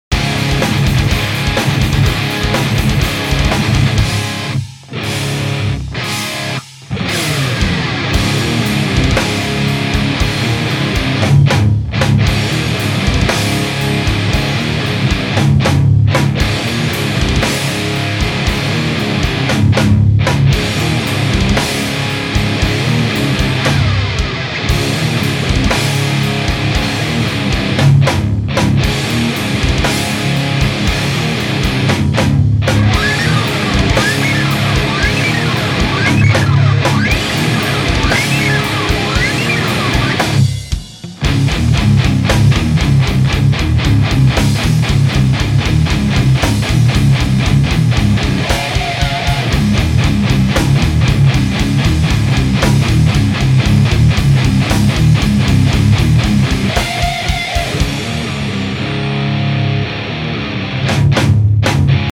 Nahrávací studio v Lipově audio / digital